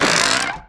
Taunt_luxury_lounge_chair_creak.wav